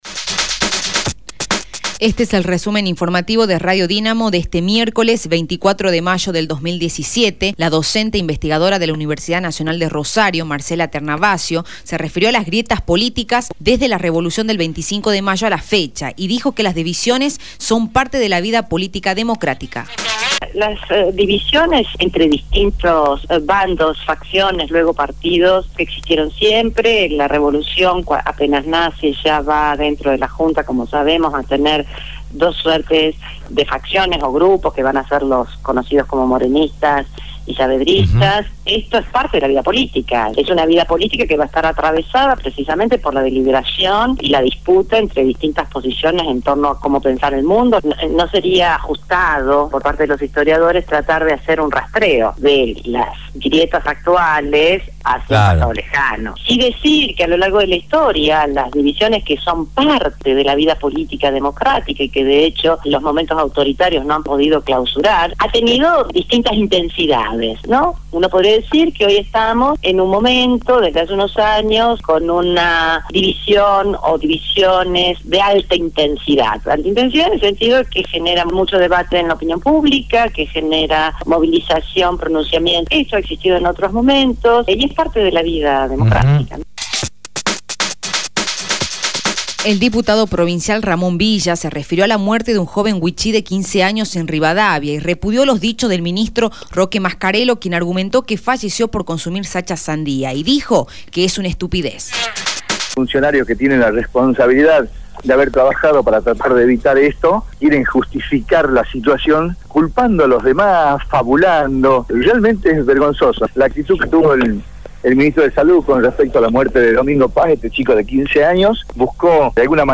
Resumen Informativo de Radio Dinamo del día 24/05/2017 1° Edición